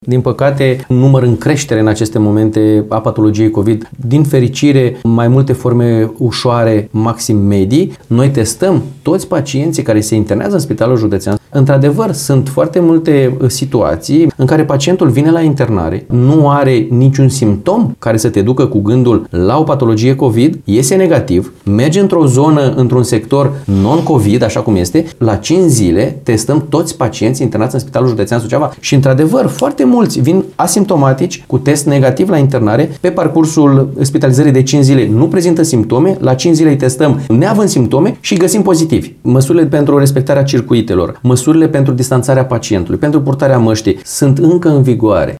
în cadrul unei emisiuni la postul nostru